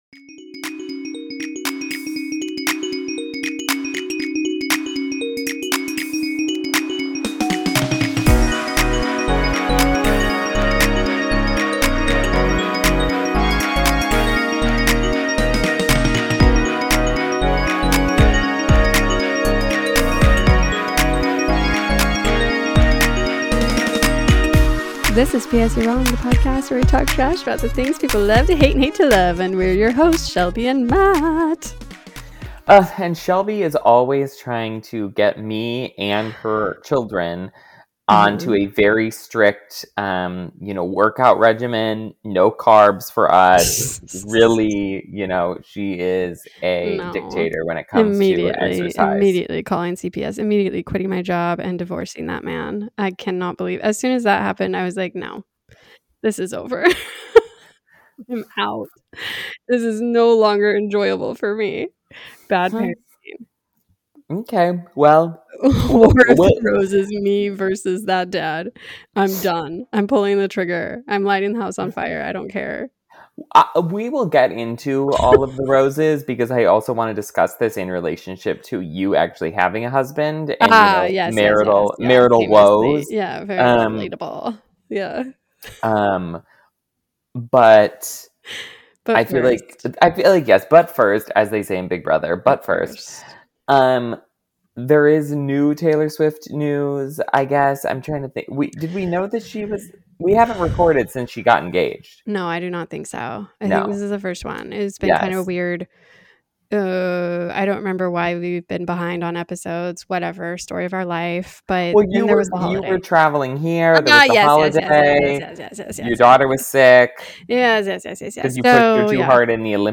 This weekly podcast finds two friends who are constantly at odds coming together to talk all things pop culture.